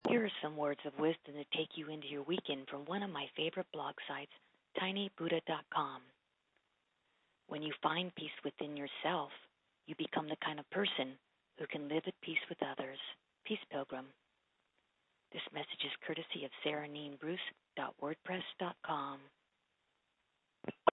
Voice Recording